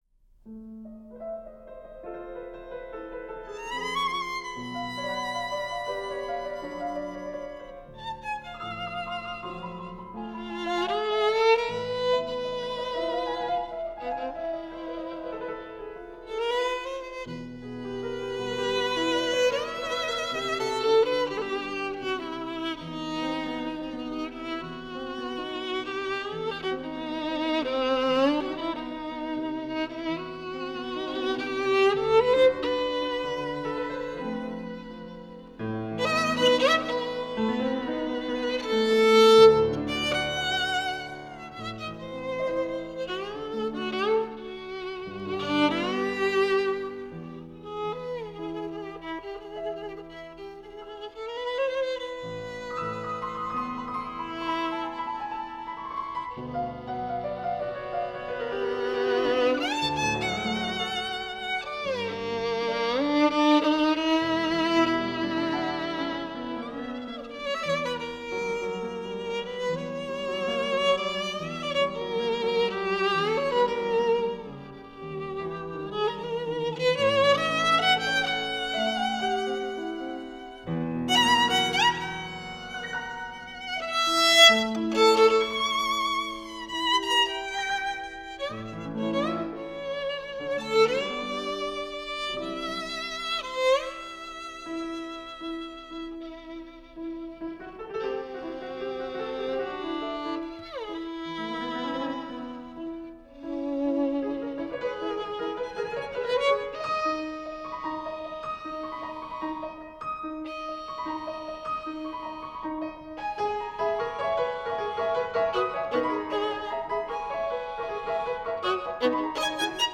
violinist educator composer
04-g-enescu-sonata-no-3-i.m4a